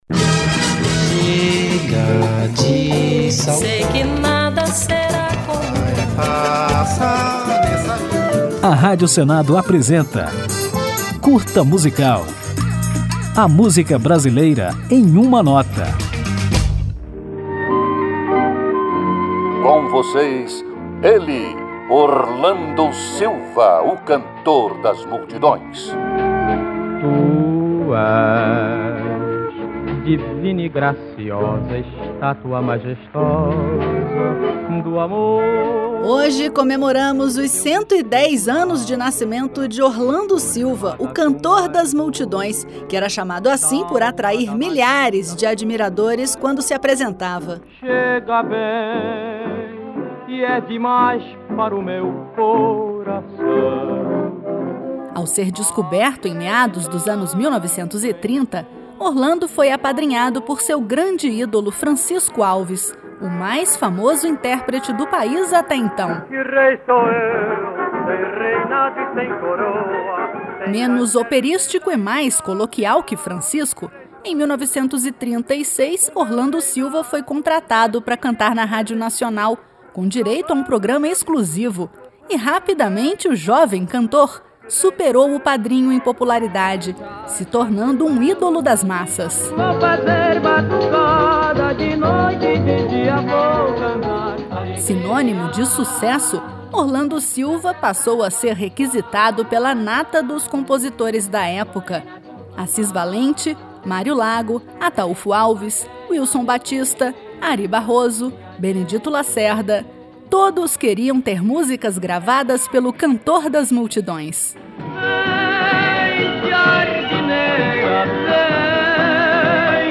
Depois de conferir a história de Orlando Silva, vamos encerrar esta homenagem com um de seus primeiros sucessos, a música Lábios que Beijei, de 1937.